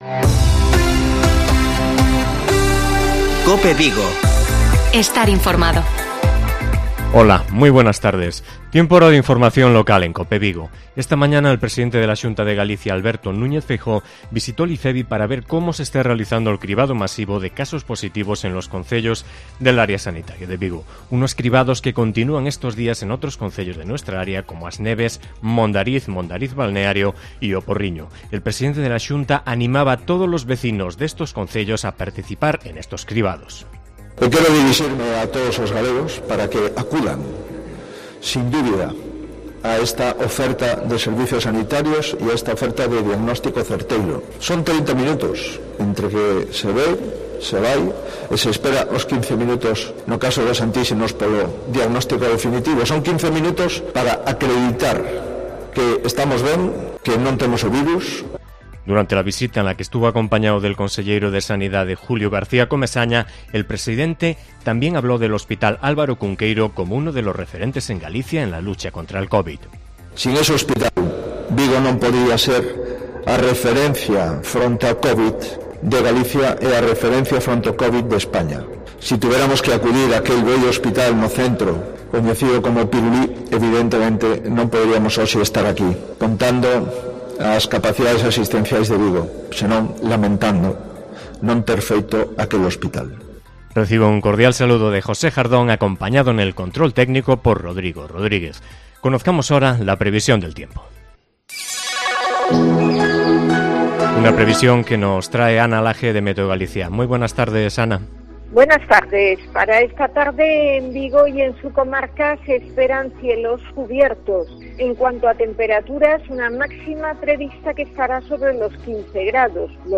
INFORMATIVO COPE VIGO 05/02/2021